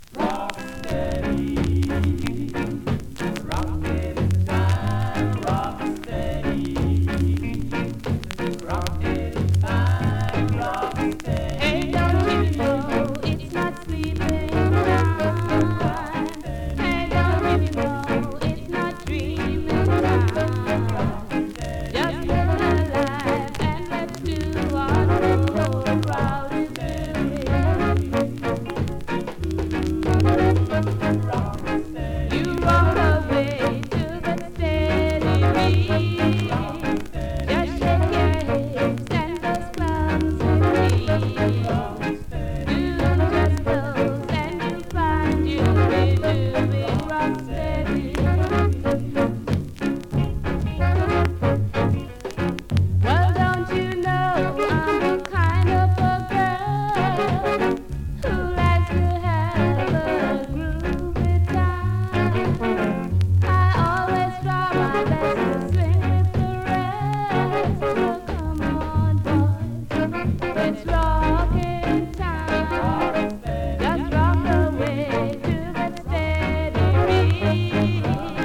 うすくノイズとエッジワープ有)   コメントレアROCKSTEADY!!
スリキズ、ノイズ比較的少なめで